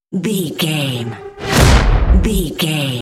Dramatic whoosh to hit trailer
Sound Effects
Fast paced
Atonal
dark
intense
tension
woosh to hit